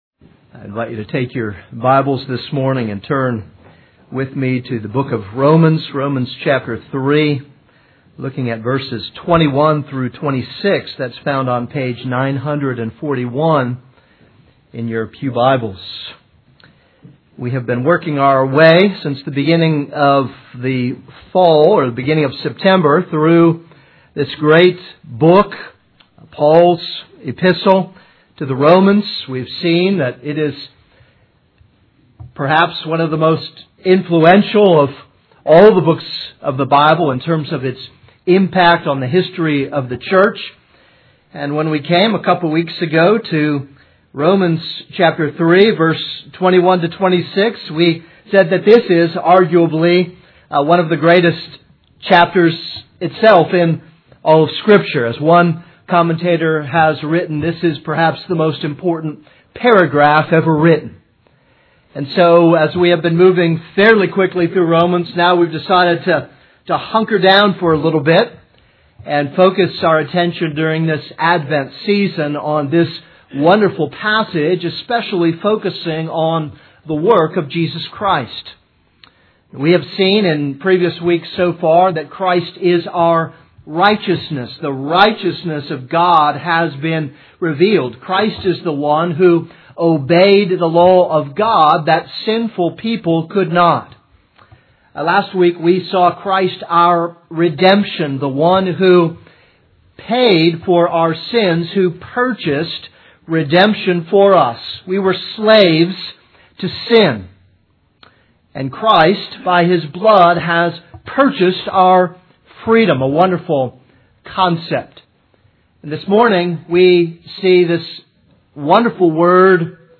This is a sermon on Romans 3:21-26.